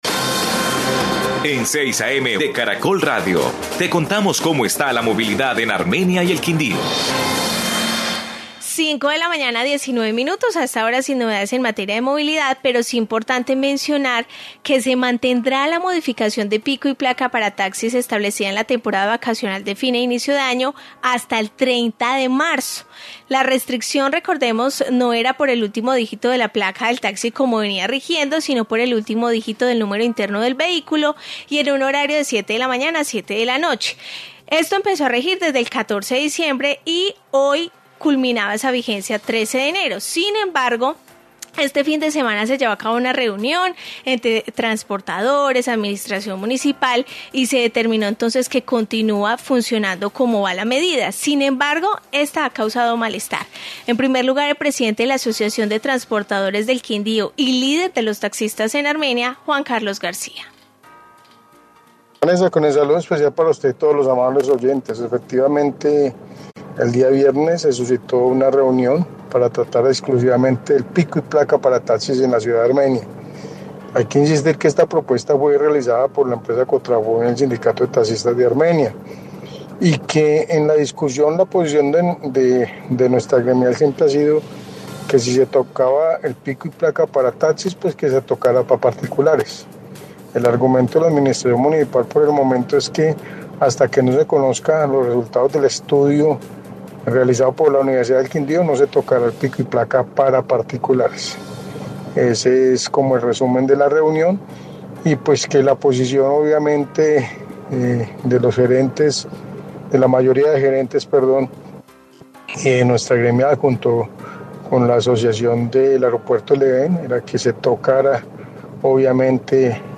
Informe modificación de pico y placa para taxis